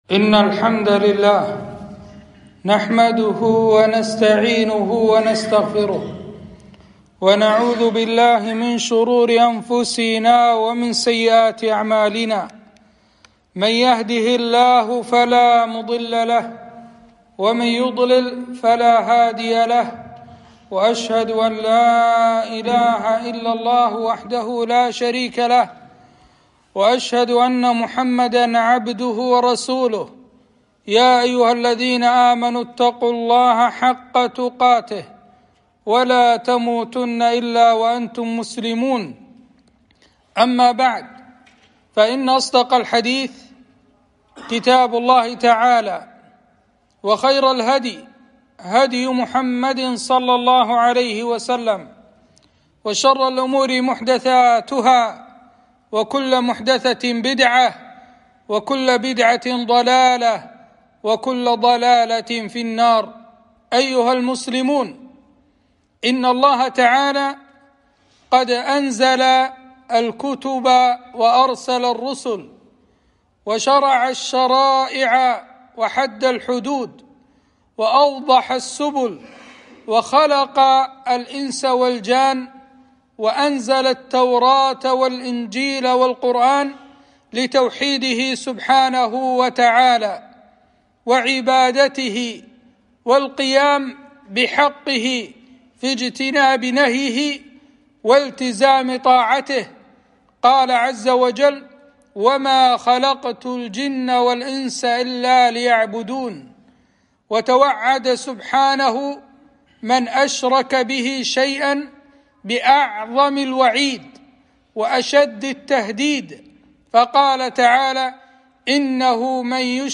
خطبة - خطورة الشرك